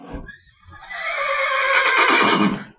Horse Whinny 12